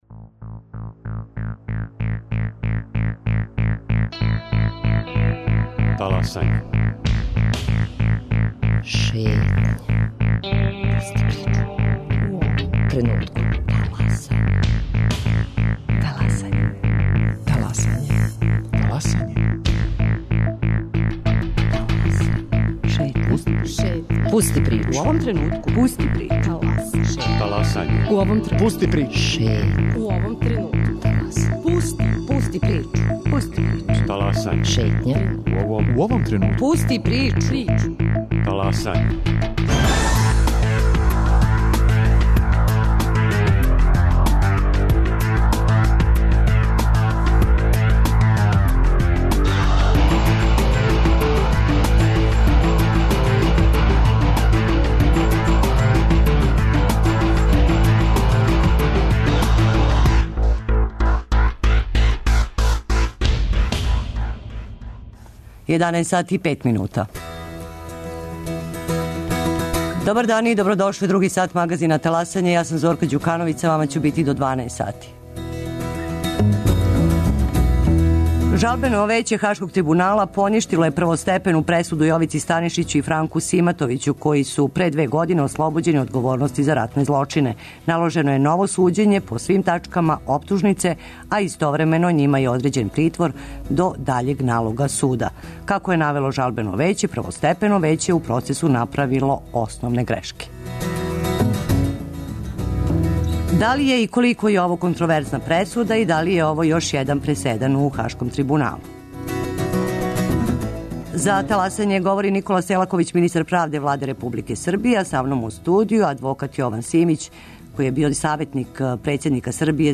У разговору за Таласање Радио Београда 1 министар правде Никола Селаковић је рекао да је пресуда жалбеног већа Хашког трибунала којом је укинута ослобађајућа пресуда Јовици Станишићу и Франку Симатовићу у најмању руку изненађујућа: Изненађујућа из разлога што одступа од претходно постављених принципа и стандарда које је поставио сам Хашки трибунал.